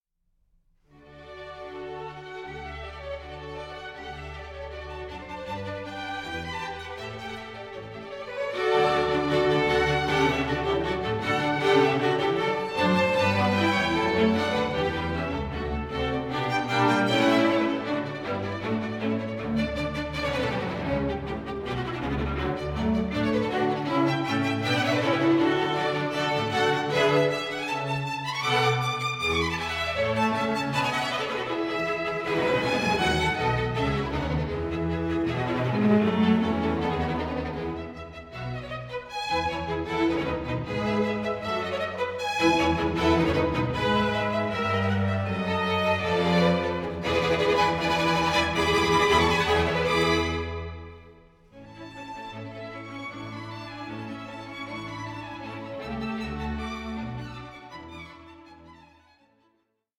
taut intensity and contrapuntal precision